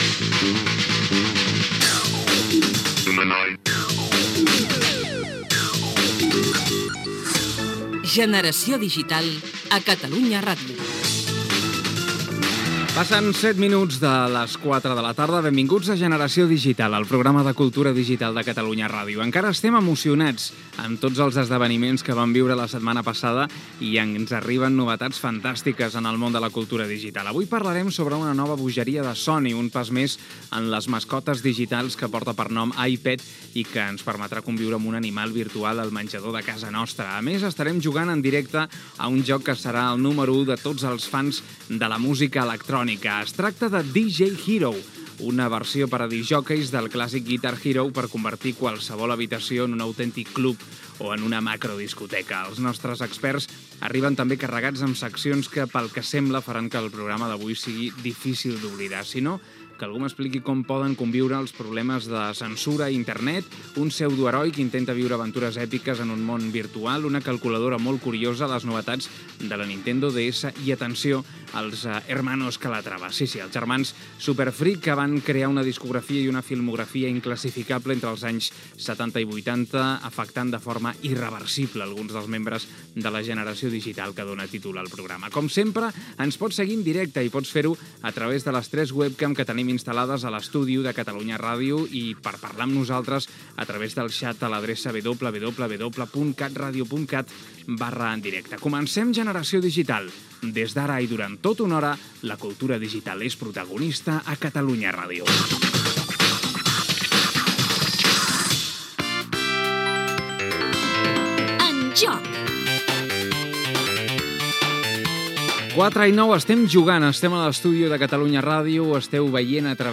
Indicatiu del programa, hora, sumari de continguts, "En joc" el videojoc "DJ Hero", premis per als oïdors, formes de veure i escoltar el programa, videojocs musicals
Divulgació